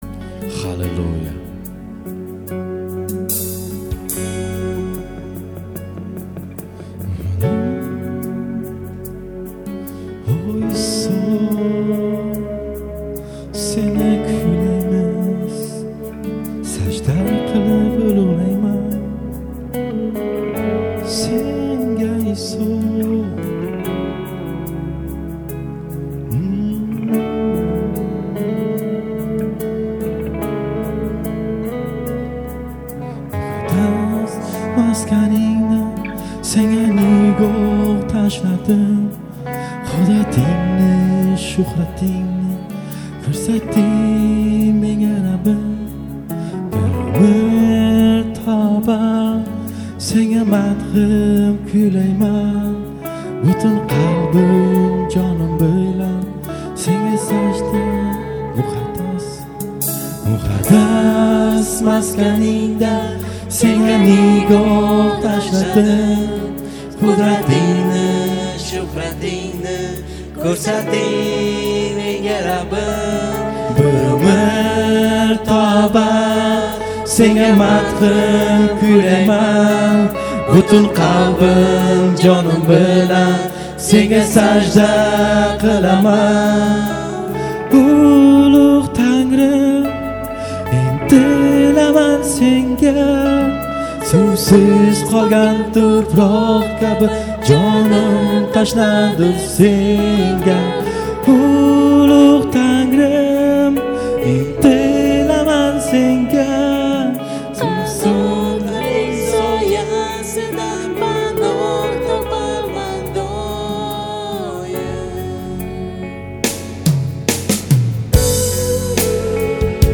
89 просмотров 41 прослушиваний 2 скачивания BPM: 70